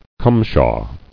[cum·shaw]